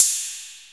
808-Ride3.wav